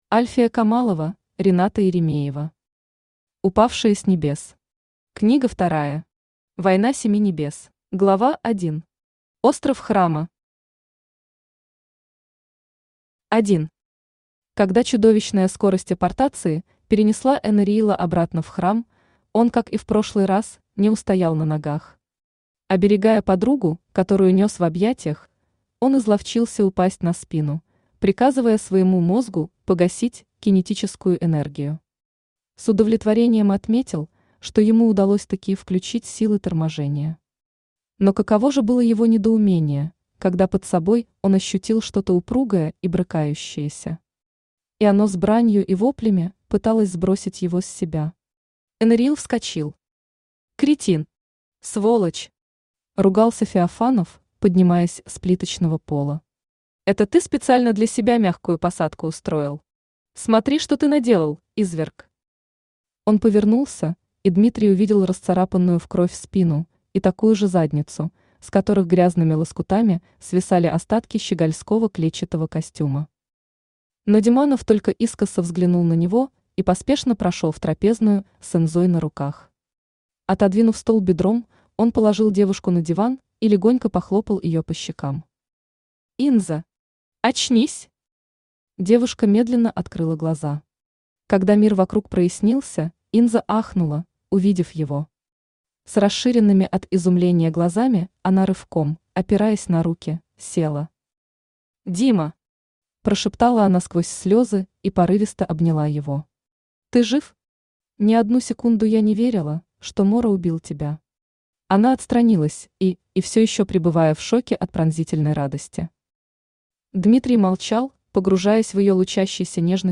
Война Семи Небес Автор Рената Еремеева Читает аудиокнигу Авточтец ЛитРес.